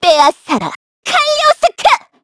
Xerah-Vox_Skill1_kr_c_Madness.wav